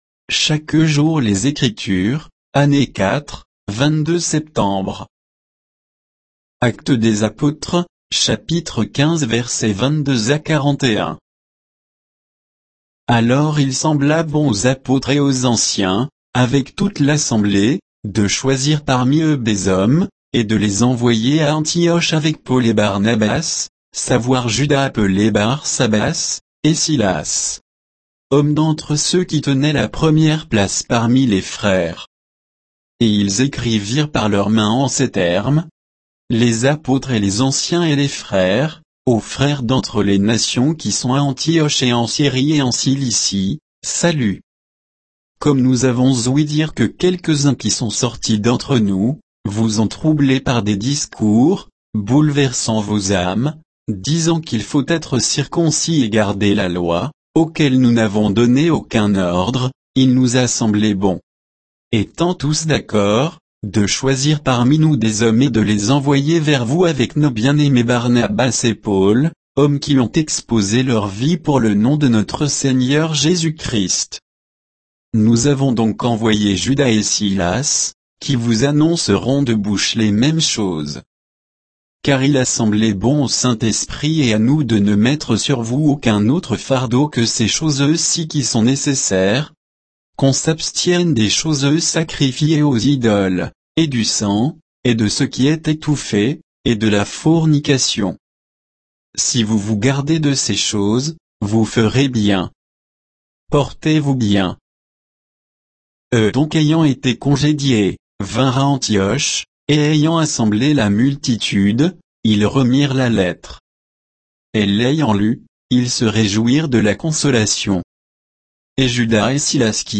Méditation quoditienne de Chaque jour les Écritures sur Actes 15, 22 à 41